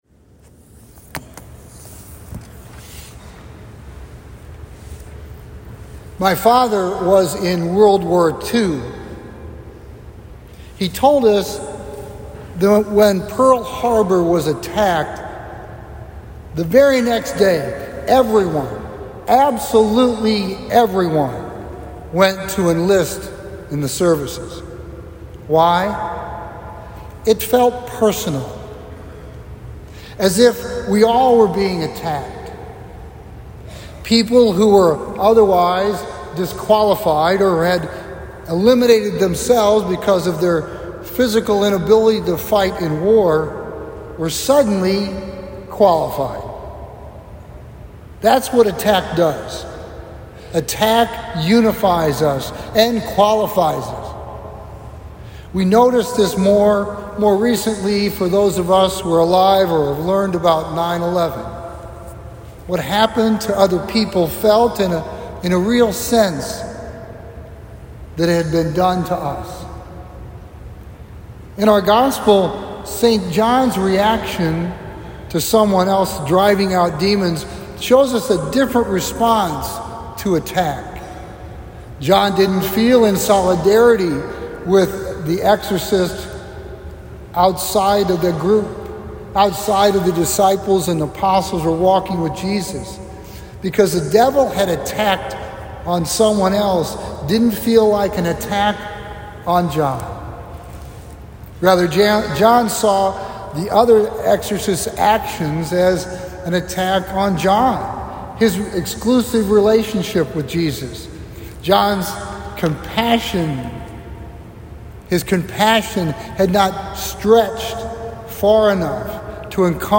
Homily February 26, 2025